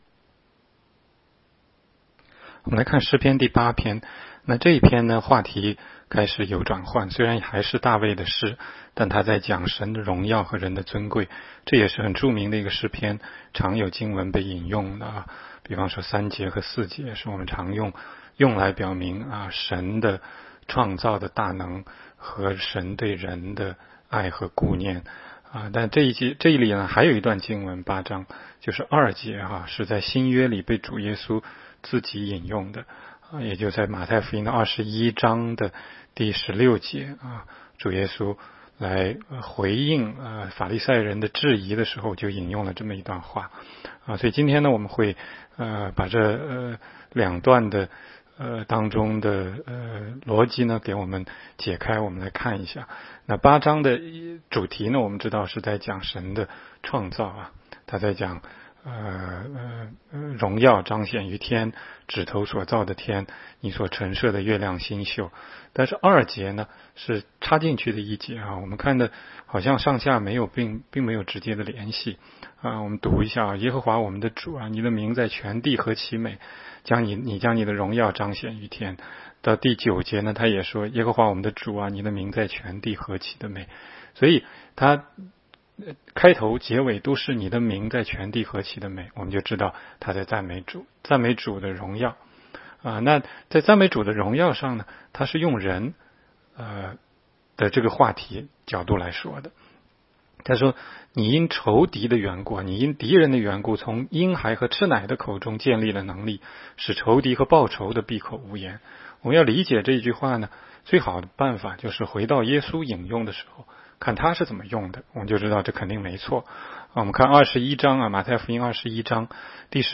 16街讲道录音 - 每日读经-《诗篇》8章